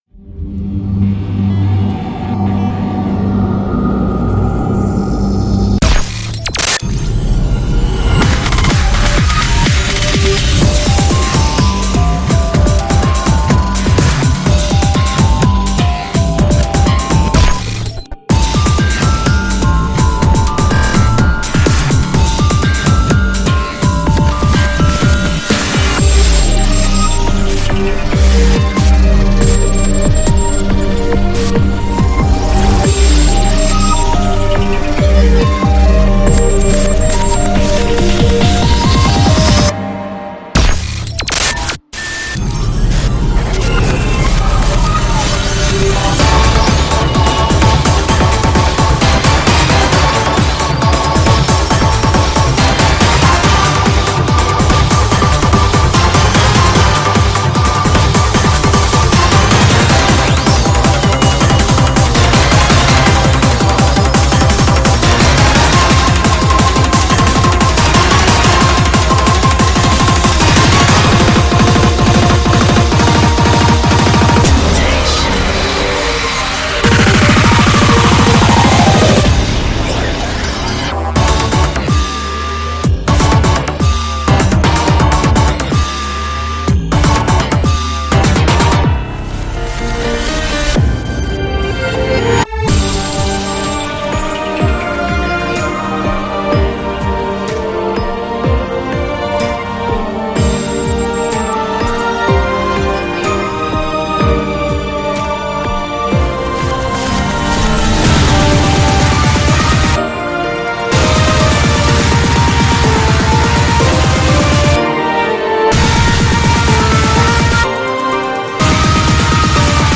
BPM140-399
Audio QualityPerfect (High Quality)